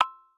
metronomelow.wav